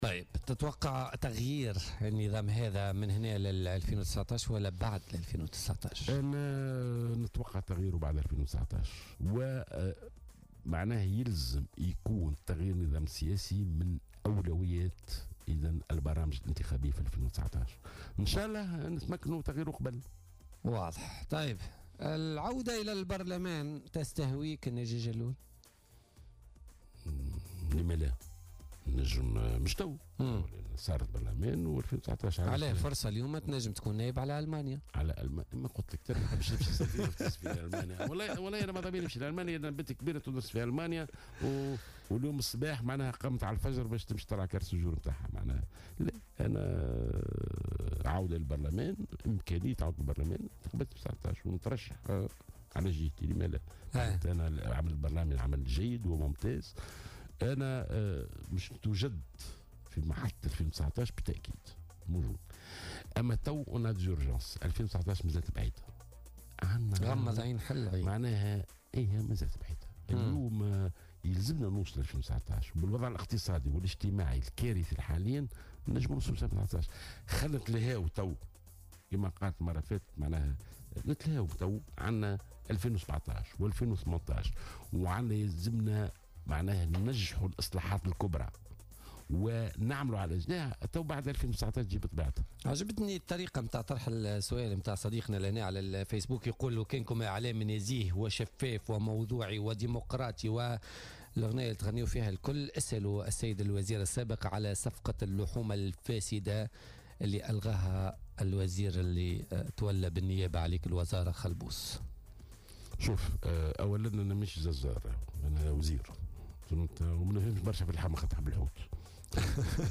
قال وزير التربية السابق ناجي جلول ضيف بولتيكا اليوم الإثنين إن صفقة اللحوم الفاسدة التي قيل أن الوزير بالنيابة الذي مسك الوزارة بعد اقالته أن الصفقات لا يمضيها الوزير بل لجنة الشراءات في كل مؤسسة تربوية .